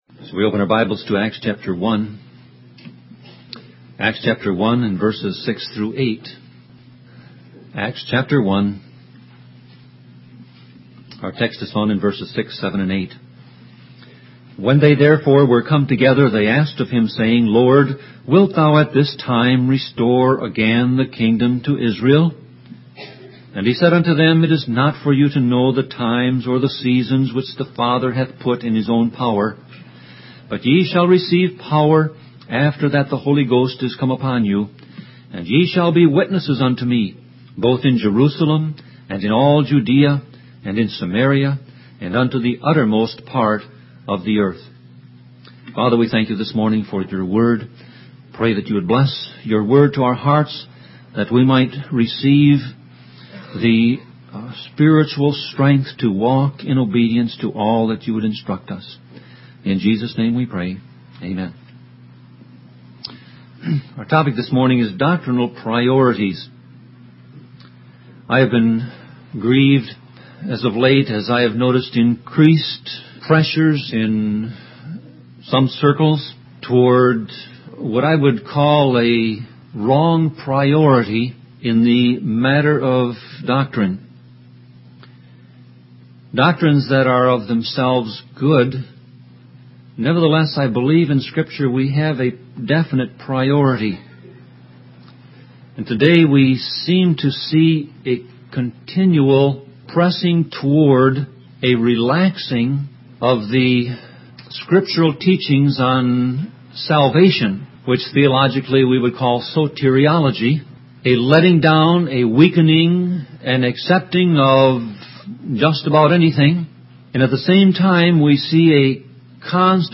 Sermon Audio Passage: Acts 1:6-8 Service Type